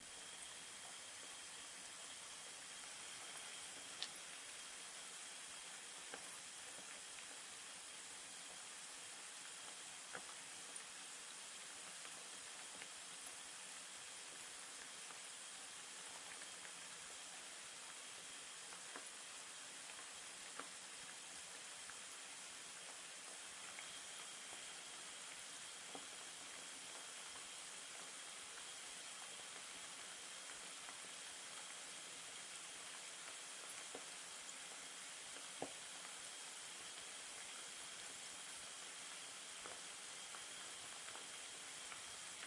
作用 " 在油中煎炸 - 声音 - 淘声网 - 免费音效素材资源|视频游戏配乐下载
用油烹饪